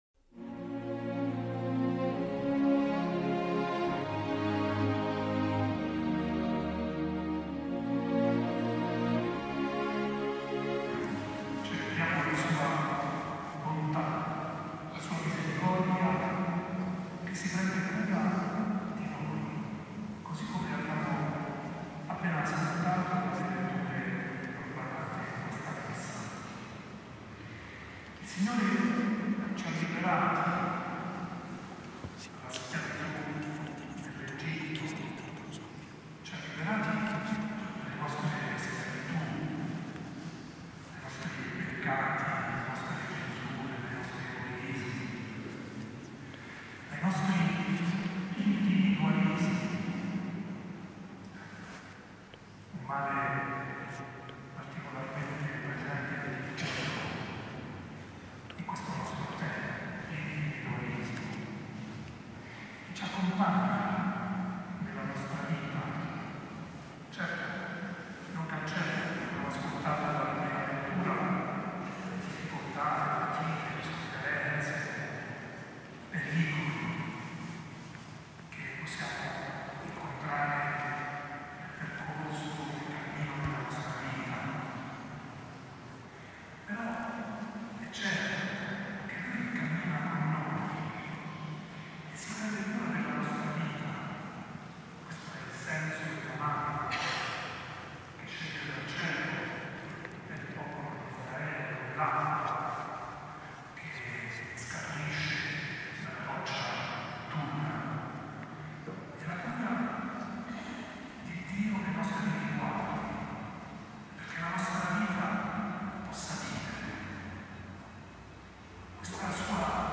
OMELIA-CORPUS-DOMINI-DI-S.E.-MONS.-LEONARDO-DASCENZO.mp3